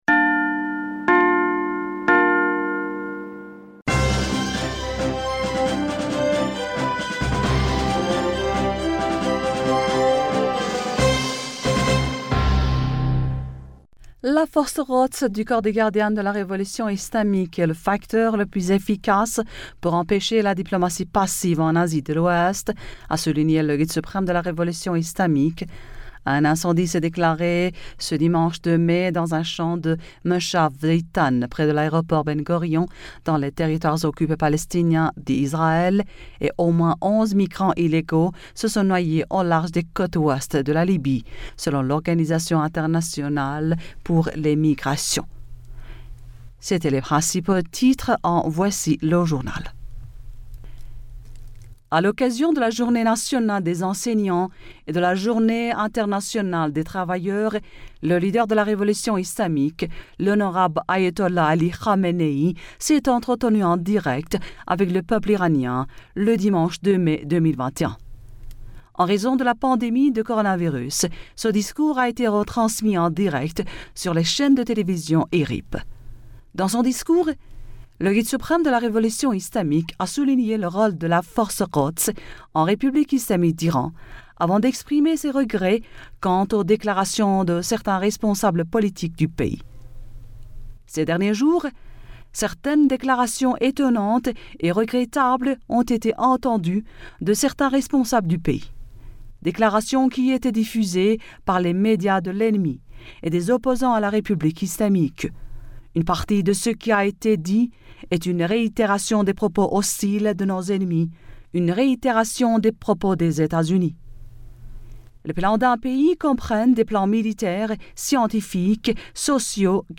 Bulletin d'information du 03 mai 2021